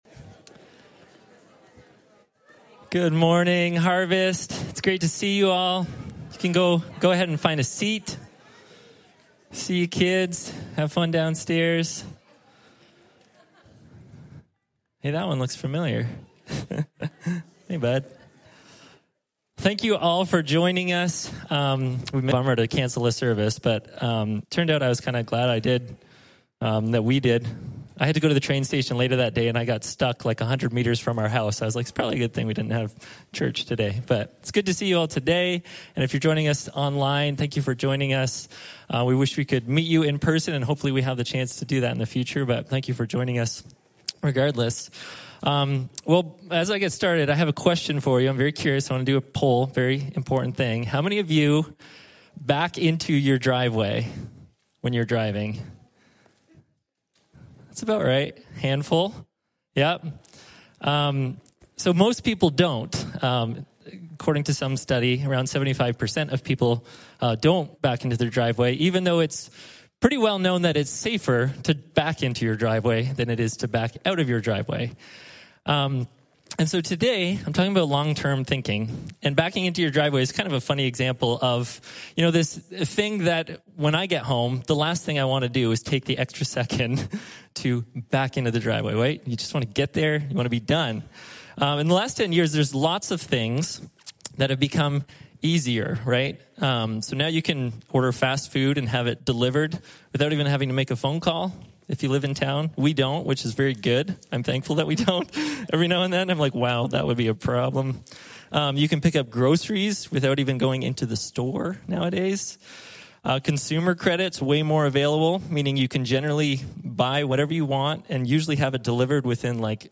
The latest messages from Harvest's weekend service in Cornwall Ontario